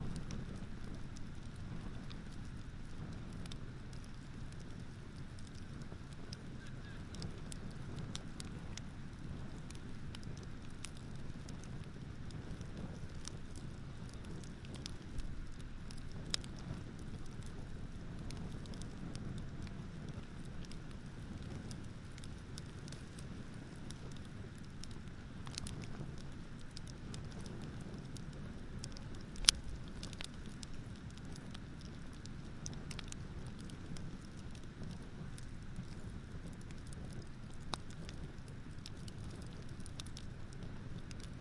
Campfire Crackling » Campfire Crackle 3
描述：More sounds of a campfire crackling.
标签： crackling campfire wood microsound fire burning
声道立体声